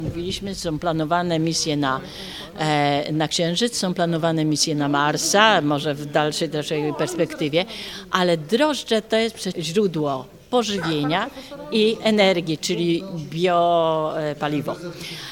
Dziś podczas konferencji prasowej w Szczecinie naukowcy zaprezentowali kopię pudełka, które przebywało na stacji kosmicznej.